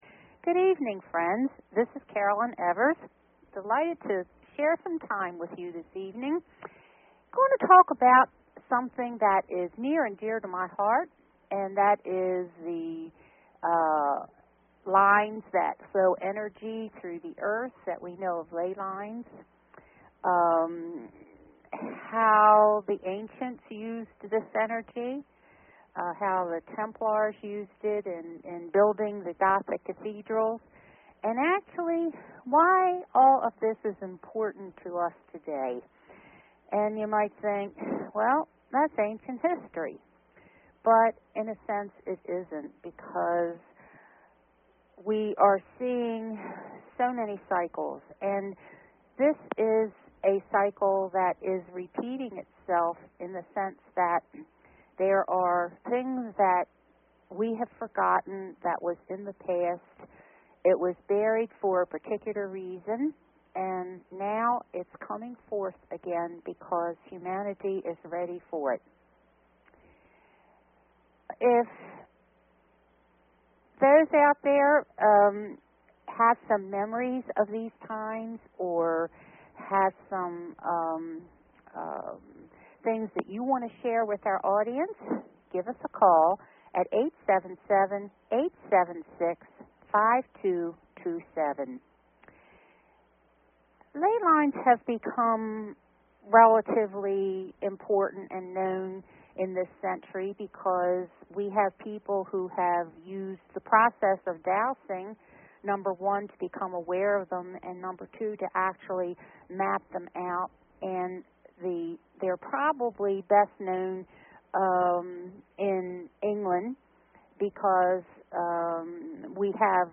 Talk Show Episode, Audio Podcast, Cosmic_Connections and Courtesy of BBS Radio on , show guests , about , categorized as
Courtesy of BBS Radio